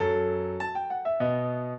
piano
minuet8-6.wav